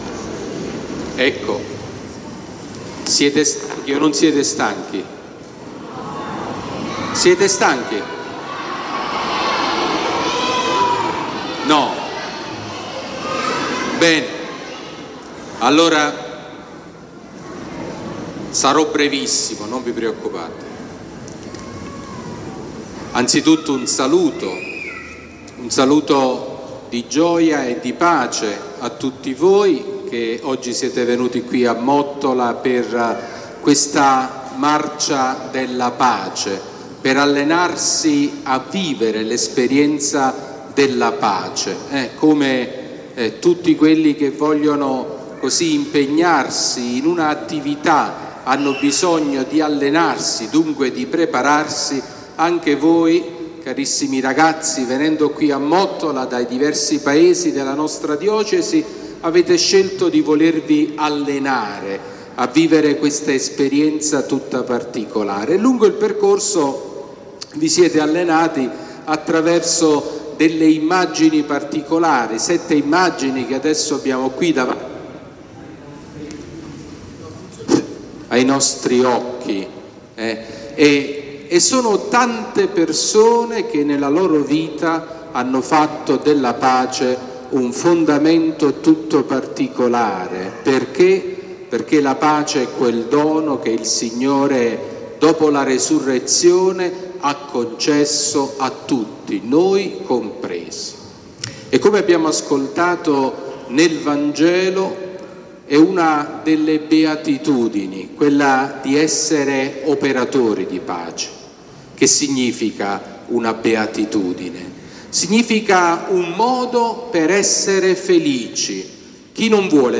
Omelia del Vescovo Sabino durante la Santa Messa per la Marcia della Pace
L'omelia di Mons. Sabino Iannuzzi durante la Santa Messa, presso la Parrocchia di San Pietro Apostolo di Mottola, per la Marcia della Pace.
Omelia-Vescovo-Sabino-marcia-della-pace-2023.mp3